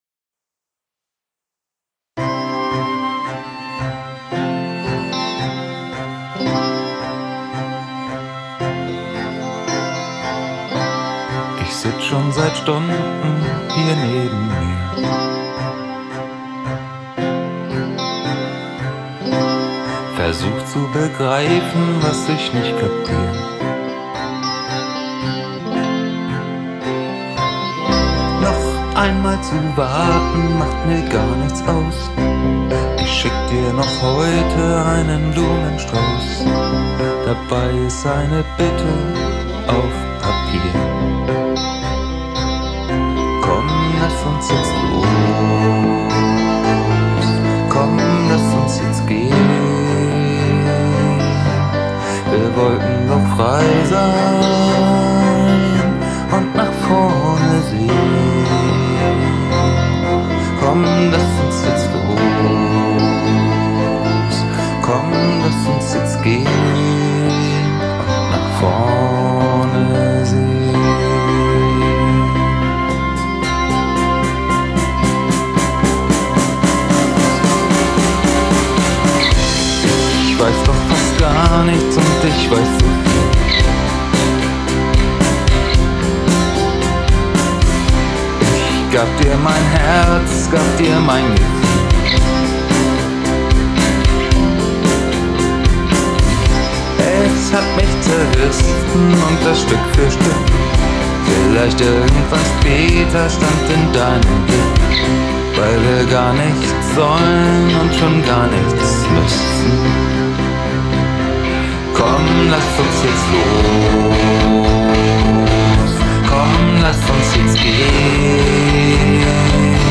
Der letzte Refrain ist schon besser geworden. Allerdings klingt er immer noch etwas unsauber. Ich weiß nicht, ob es daran liegt dass zu vieles zu laut ist oder die Kickdrum vielleicht noch zu viele Tiefen hat.
Ist das Rockig genug.